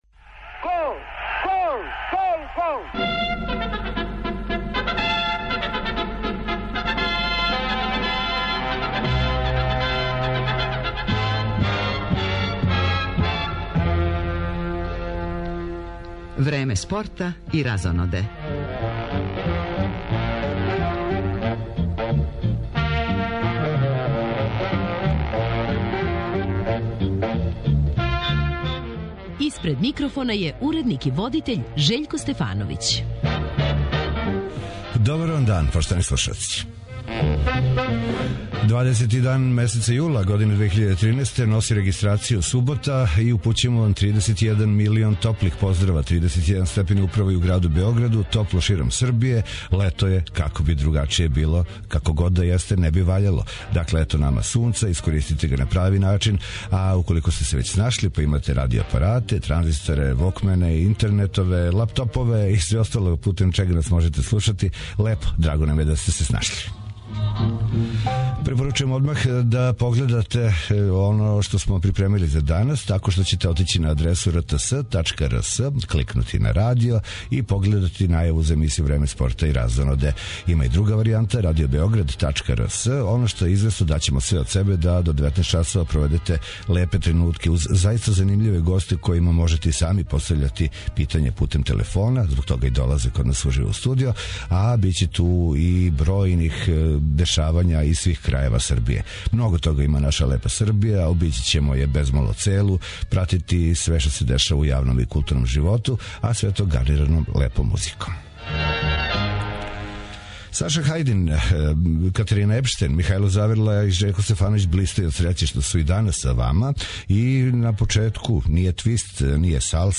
Уз одјеке учешћа наших фудбалских клубова у европским такмичењима и остале спортске актуелности, у студију ће бити плејада успешних спортиста млађе генерације.